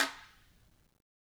Guiro-Hit_v1_rr1_Sum.wav